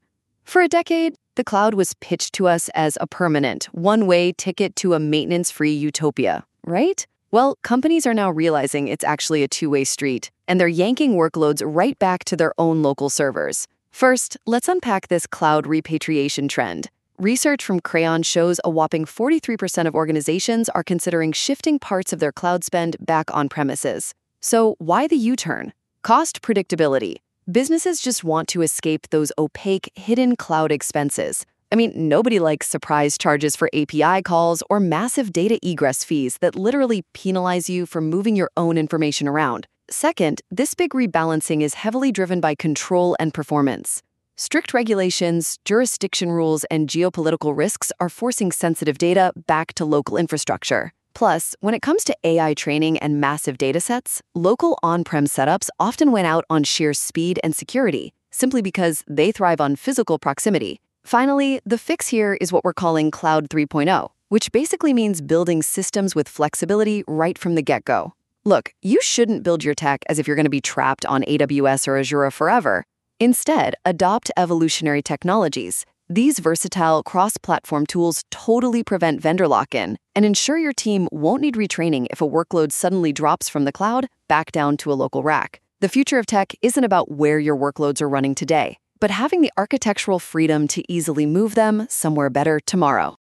Short on time? 90-second audio summary…
A speedy listen, generated using Google Notebook LM.